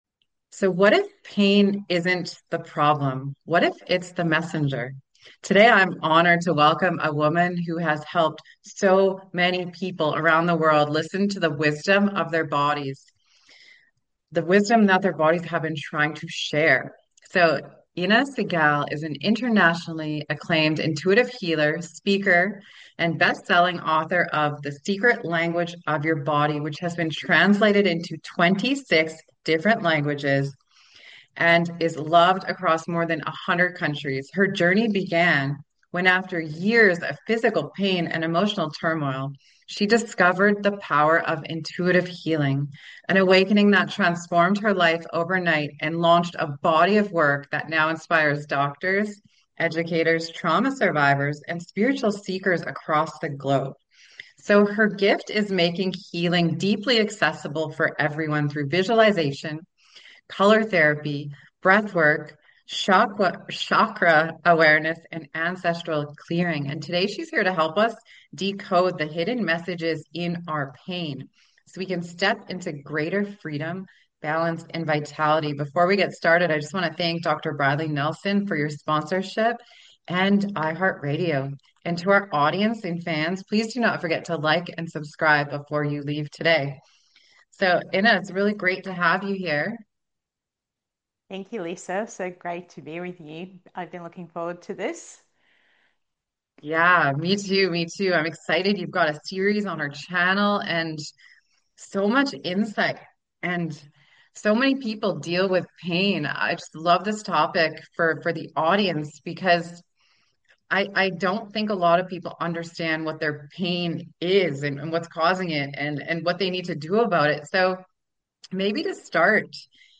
If you’ve tried “quick fixes” and still feel stuck, this conversation gives you a roadmap back to body wisdom—and the next, right step.